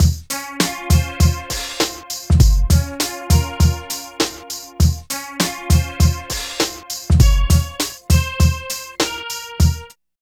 60 LOOP   -L.wav